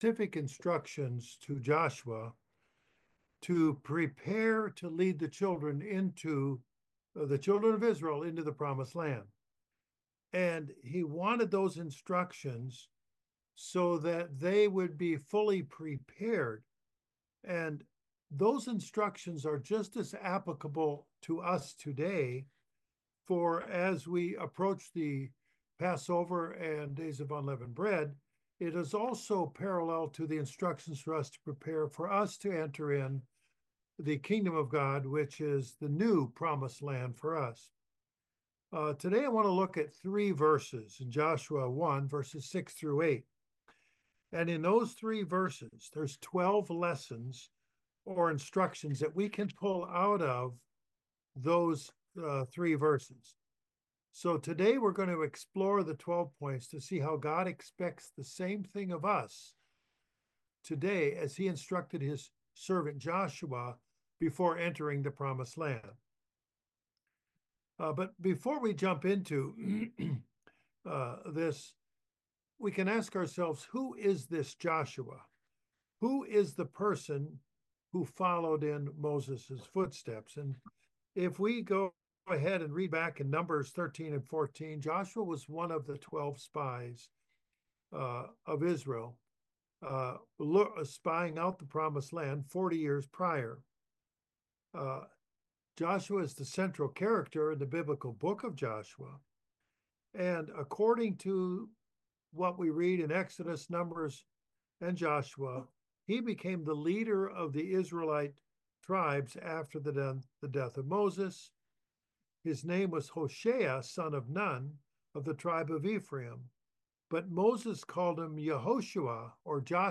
Joshua 1:6-7 Service Type: Sermon God gave specific instructions to Joshua prior to entering the Promised Land.